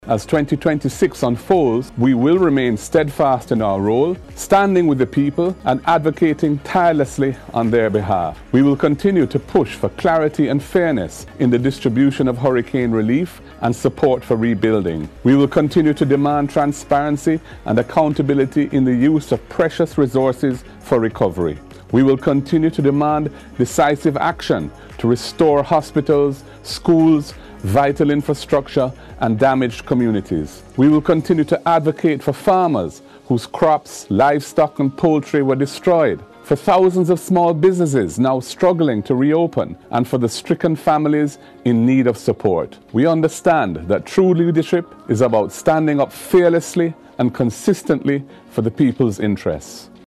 Speaking during his New Year's message Mr Golding noted that his party will continue to advocate for fairness in the recovery following Hurricane Melissa.